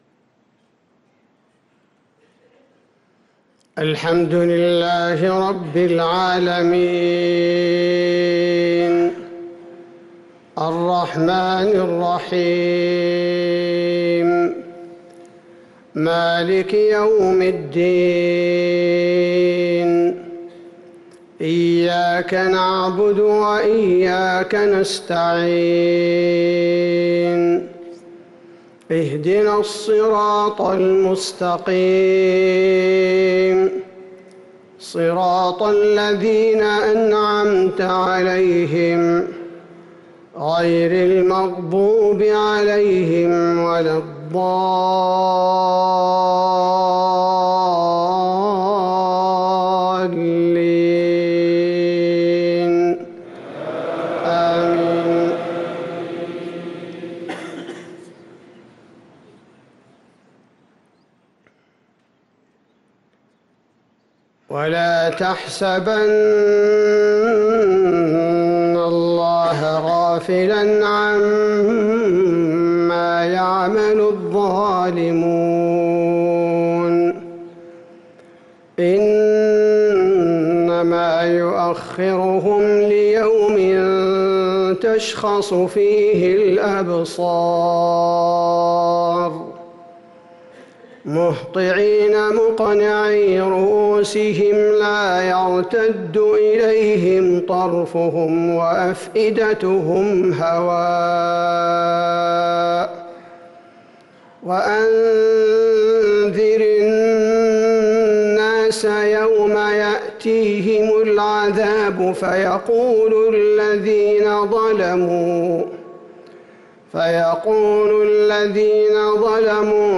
صلاة المغرب للقارئ عبدالباري الثبيتي 19 ربيع الآخر 1445 هـ